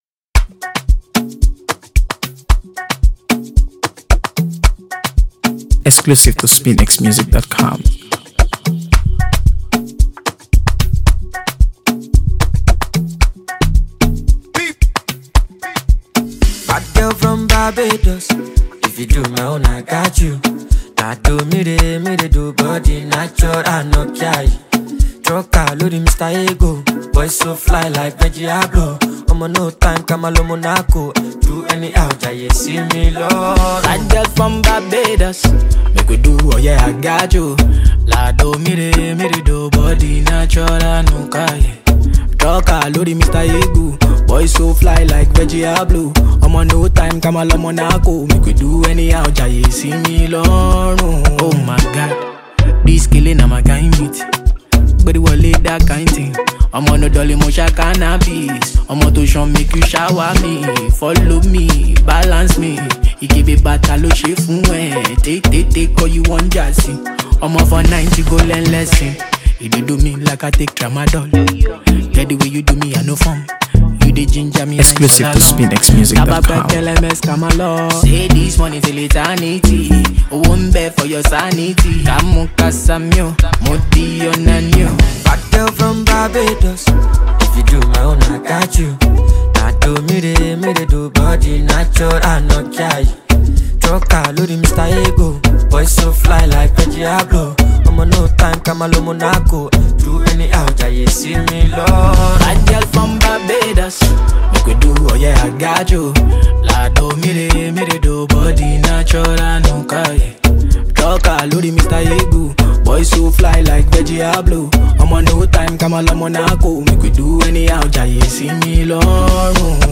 AfroBeats | AfroBeats songs
Afrobeat rhythms and catchy lyrics